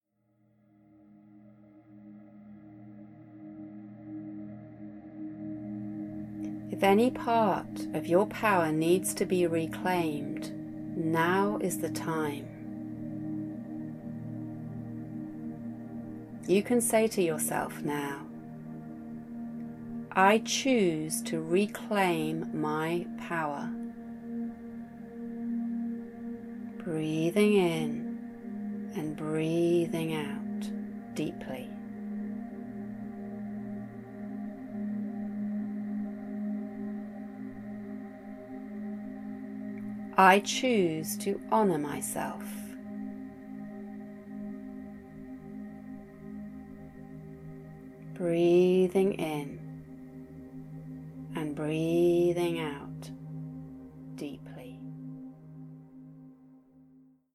In this affirmation meditation, you are invited to claim your full power and feel it in every fibre of your being.